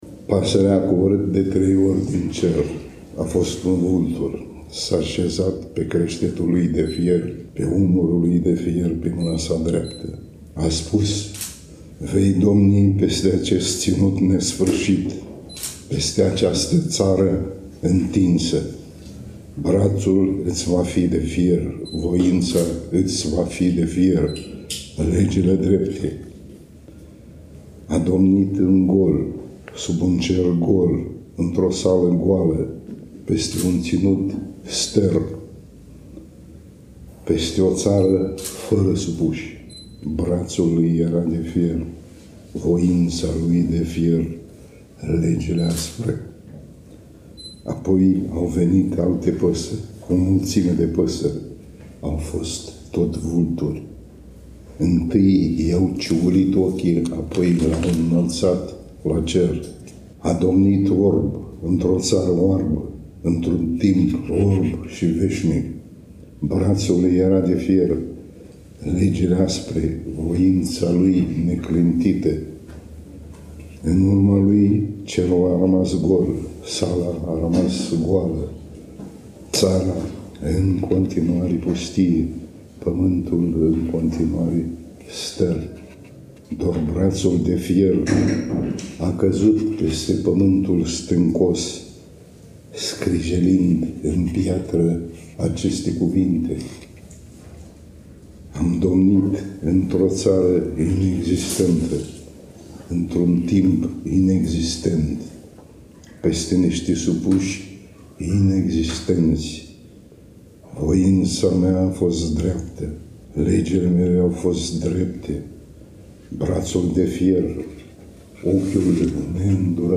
Stimați prieteni, în cele ce urmează scriitorul Nichita Danilov ne recită Vulturii orbi, poem care dă titlul volumului prezentat, la Iași, în  ziua de joi, 3 octombrie 2024, începând cu ora 18.00, în Sala „Paul Celan” din incinta Centrului Cultural German.
4_Nichita-Danilov-recita-Vulturii-orbi-2-14.mp3